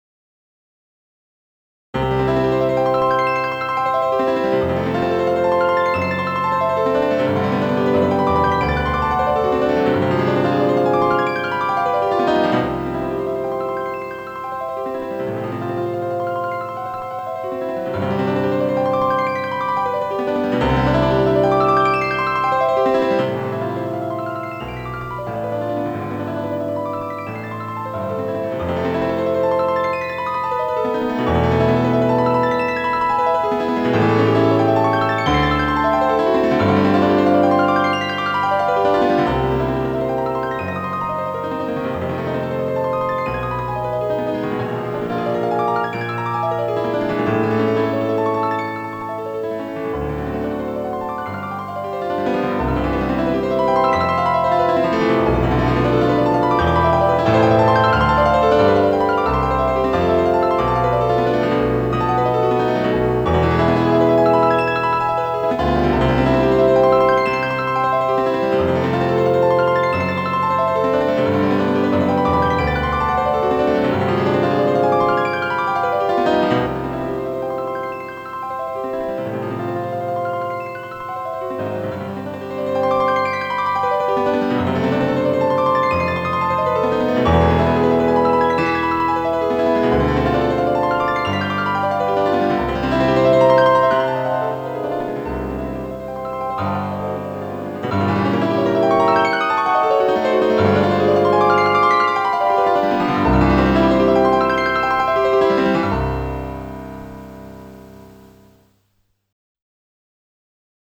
だいぶ前に作りましたが、なにしろ体験版で作ったので保存ができず、仕方がないので録音した記憶があります。
というかこういう曲しかコンピューターには演奏させるのは難しいでしょう。